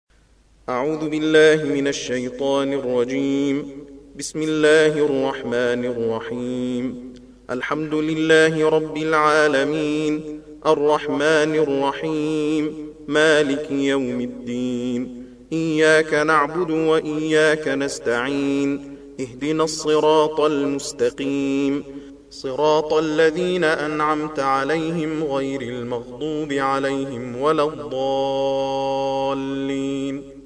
تلاوة حدر لأجزاء القرآن الكريم
استمع إلى تلاوة سريعة لأجزاء القرآن الكريم